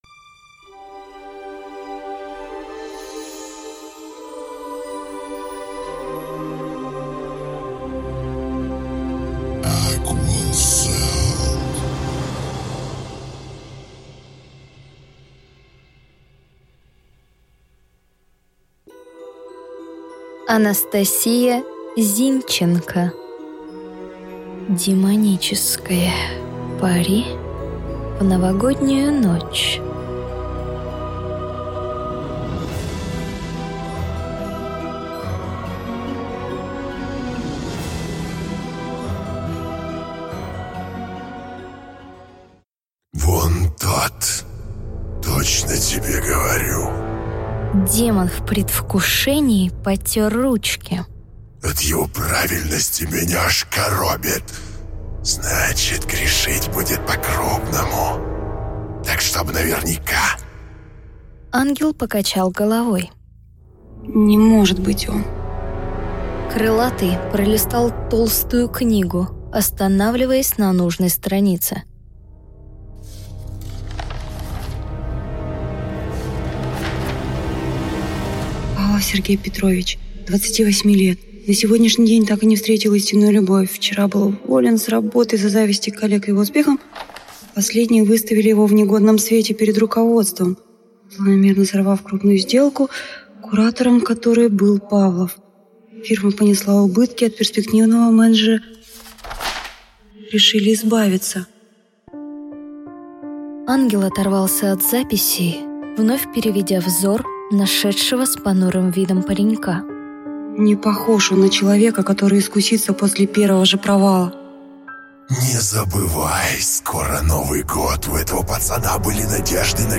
Аудиокнига Демоническое пари в Новогоднюю ночь | Библиотека аудиокниг